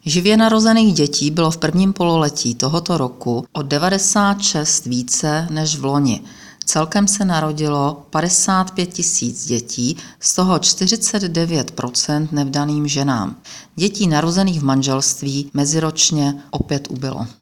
Zvukový záznam vyjádření předsedkyně ČSÚ Ivy Ritschelové, soubor ve formátu MP3, 682.7 kB